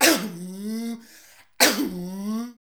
COUGH.wav